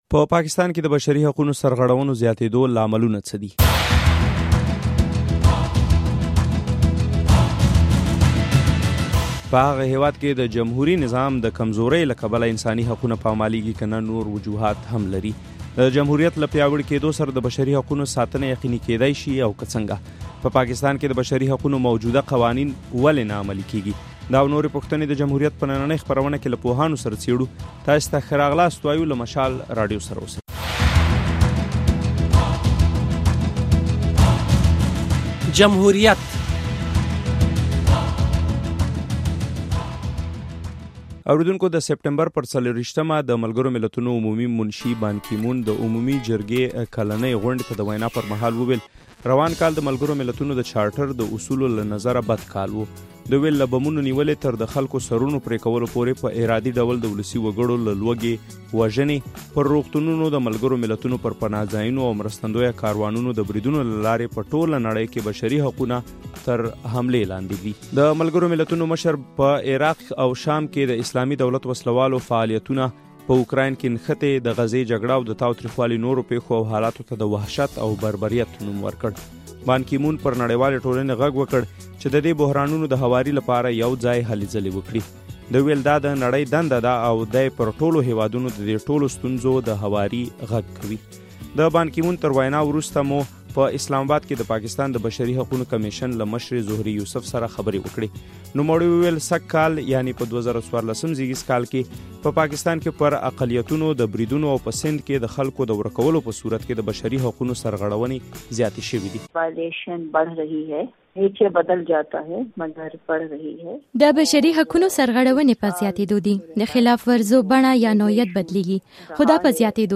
په پاکستان کې د بشري حقونو سرغړونو زیاتېدو لاملونه څه دي؟ په هغه هېواد کې د جمهوري نظام د کمزورۍ له کبله انساني حقونه پامالېږي که نه نور وجوهات هم لري؟ دا او نورې پوښتنې د جمهوریت په نننۍ خپرونه کې له پوهانو سره څېړو.